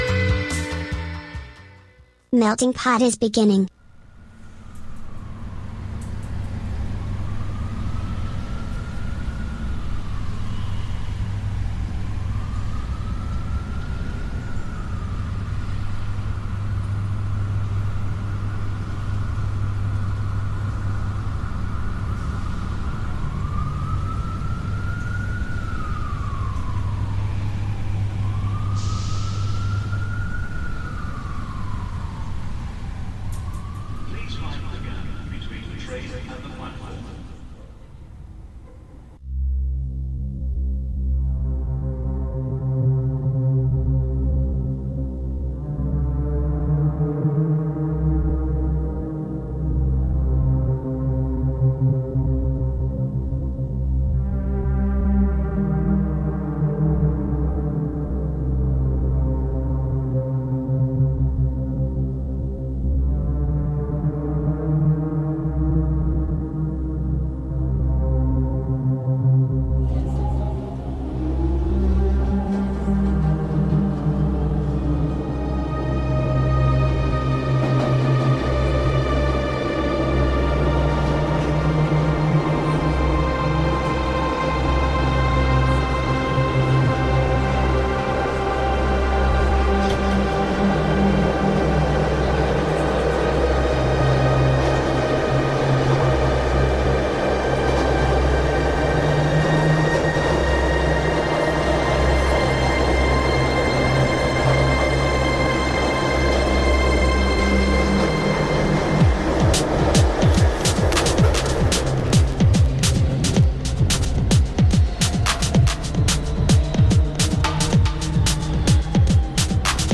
con novità discografiche, notizie e interviste.